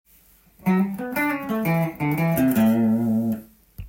混ぜたギターフレーズ集
Aミクソリディアンスケールに派生する代理コードGM７のコードトーンを
弾きながらAミクソリディアンスケールとAマイナーペンタトニックスケールを